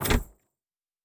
Locker 2.wav